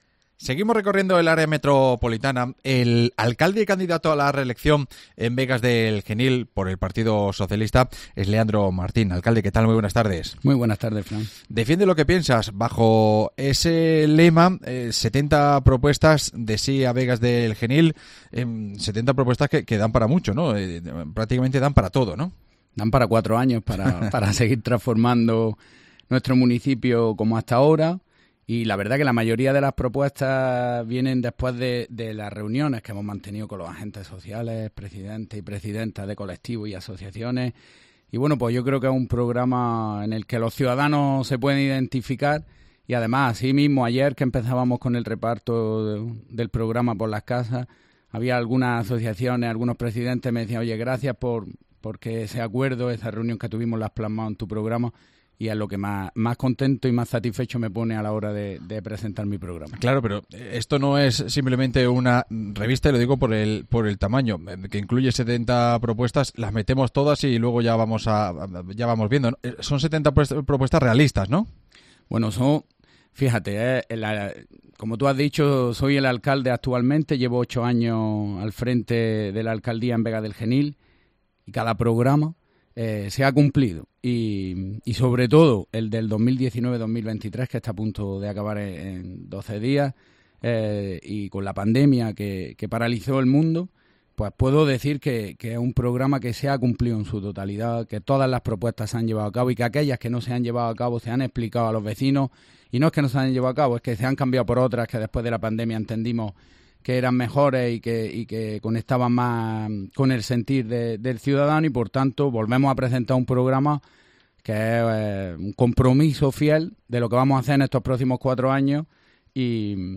AUDIO: El alcalde y candidato a la reelección de Vegas del Genil, Leandro Martín, desglosa su proyecto de ciudad en COPE